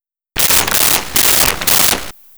Shotgun Pumps 02
Shotgun Pumps 02.wav